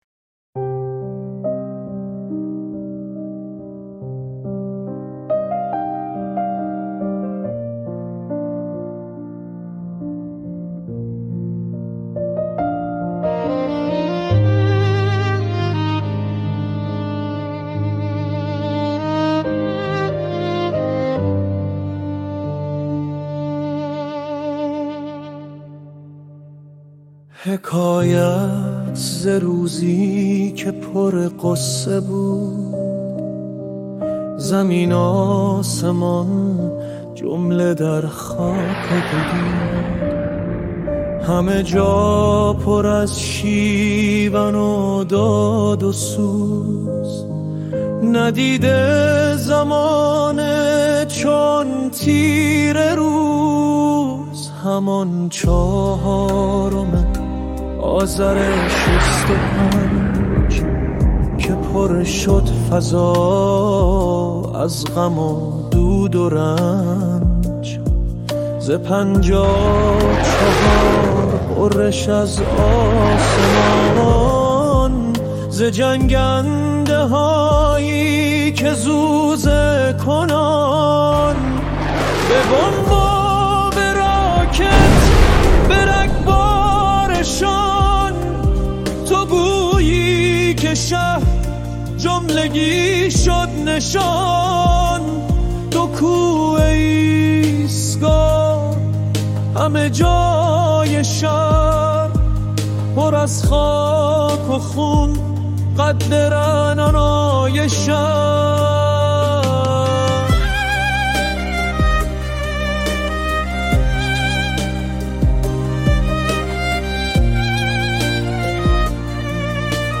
قالب شرکت در جشنواره موسیقی